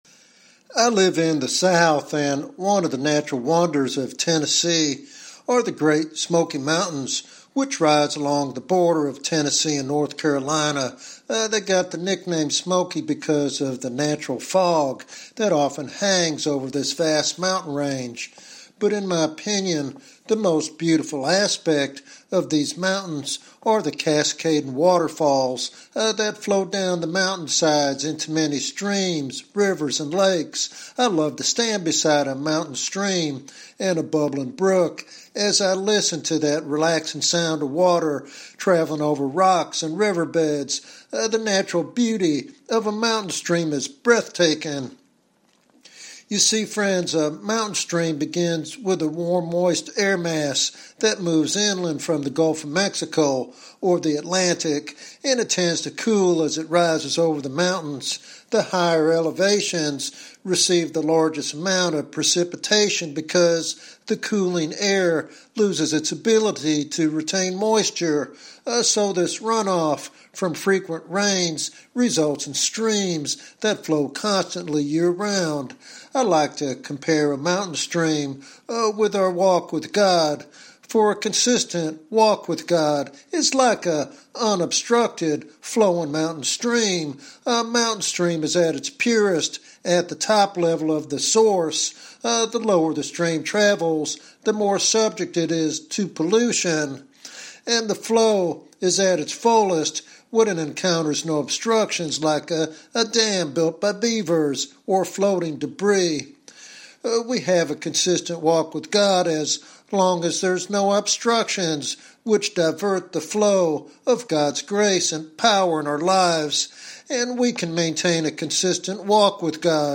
This devotional sermon inspires believers to pursue a vibrant spiritual life that impacts others and honors God.